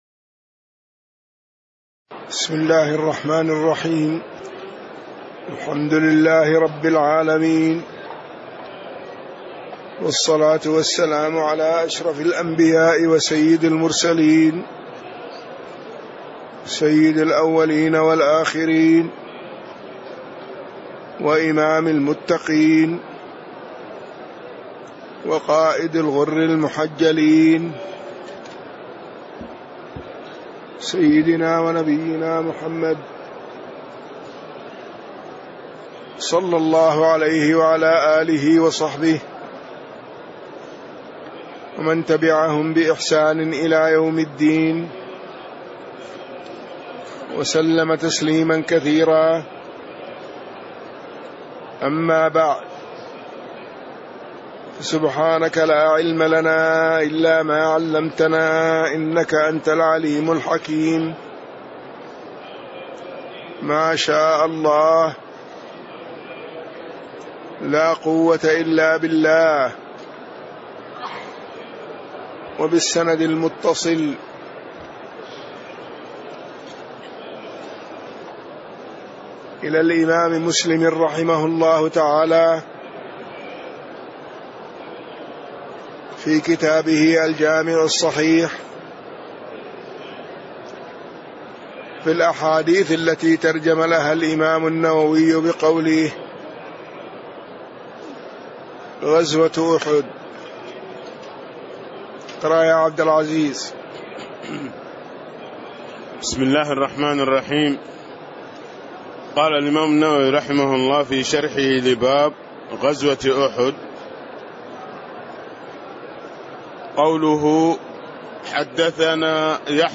تاريخ النشر ٣ محرم ١٤٣٦ هـ المكان: المسجد النبوي الشيخ